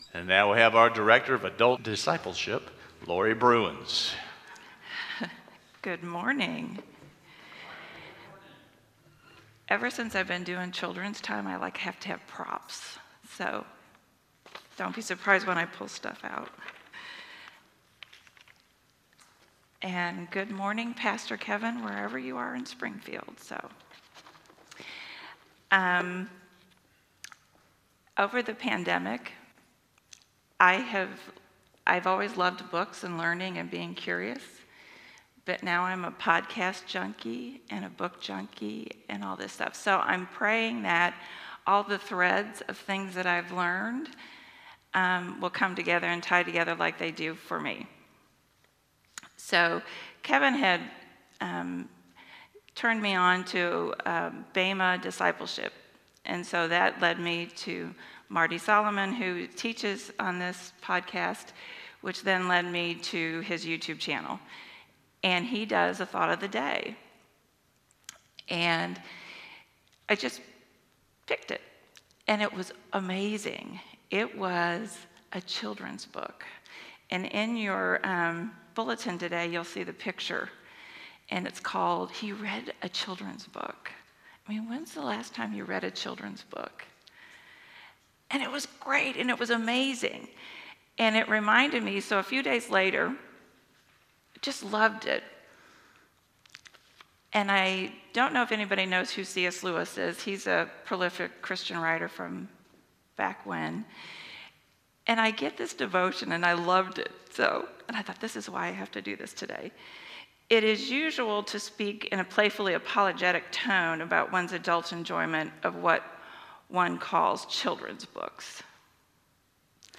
Sermons | Harrisonville United Methodist Church